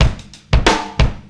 Jamaica1 93bpm.wav